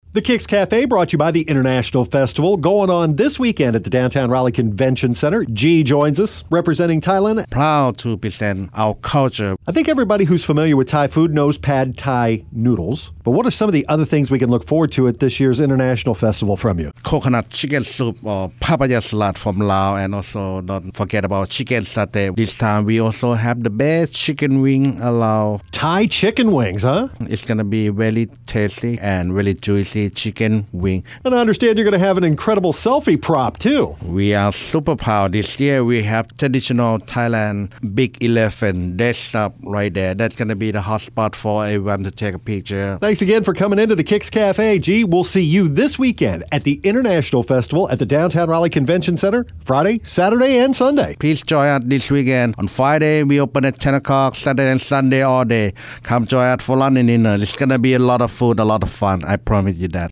three KIX Kafe radio interviews